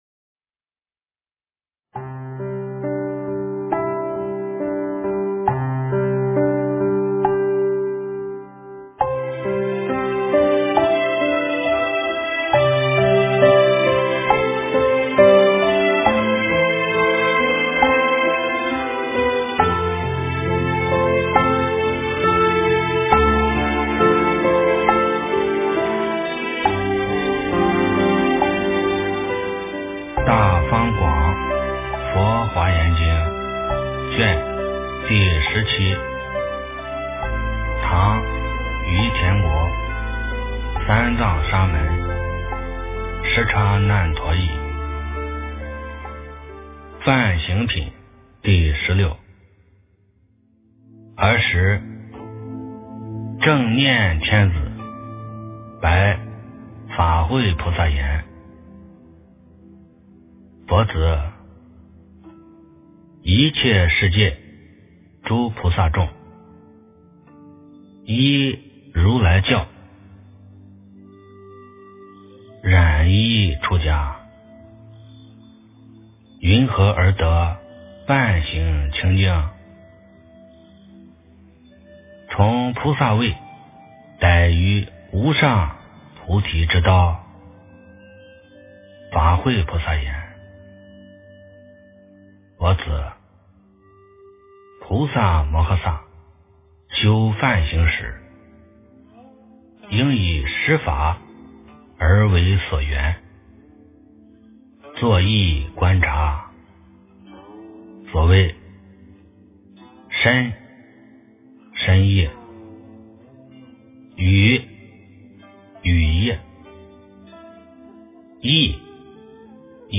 诵经